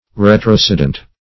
Search Result for " retrocedent" : The Collaborative International Dictionary of English v.0.48: Retrocedent \Re`tro*ced"ent\, a. [L. retrocedens, p. pr.]
retrocedent.mp3